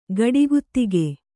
♪ gaḍi guttige